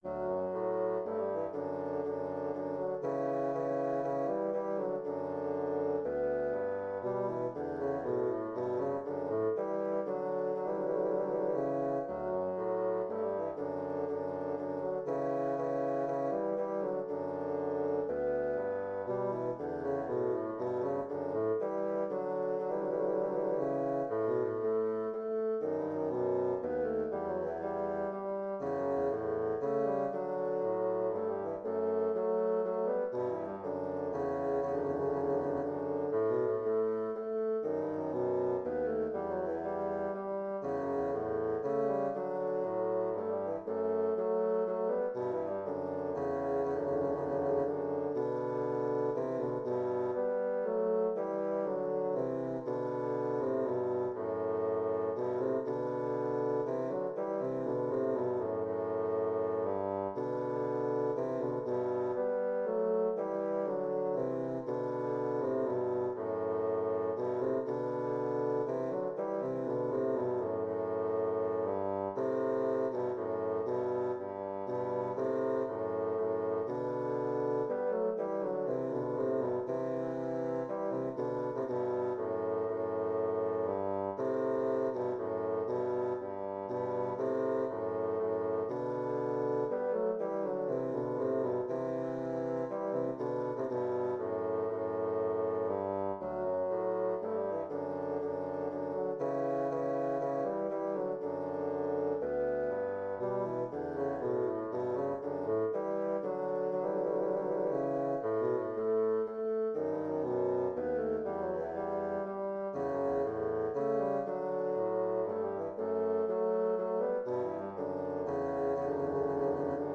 Intermediate bassoon duet